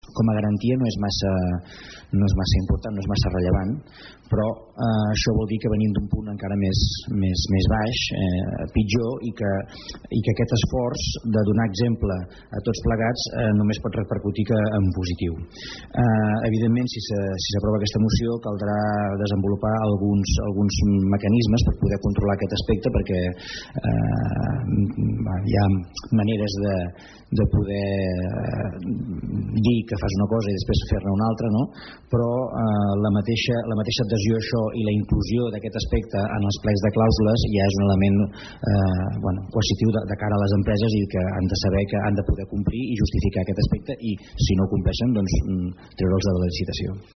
Són declaracions del portaveu d’ERC, Francesc Alemany, on apuntava que en cas que les empreses no ho complissin podria ser  motiu per no adjudicar licitacions.